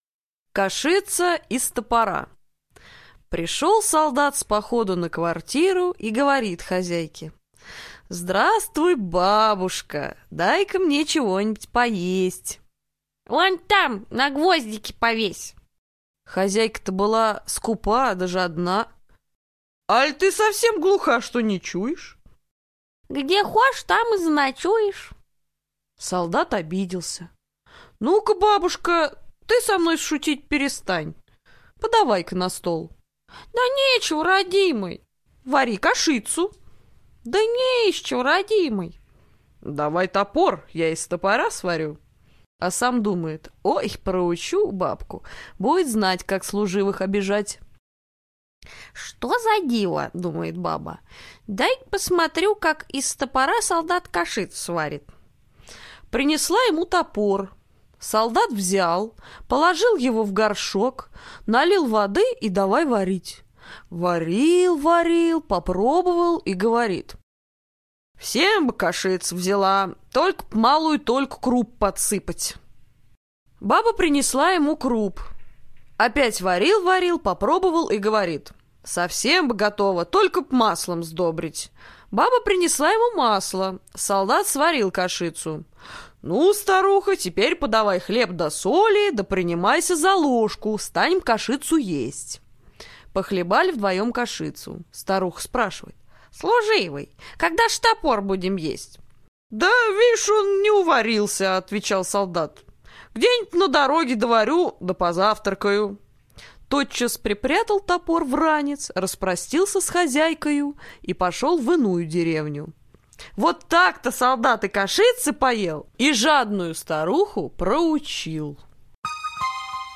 Кашица из топора - латышская аудиосказка - слушать онлайн